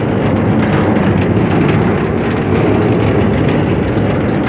level_platform.wav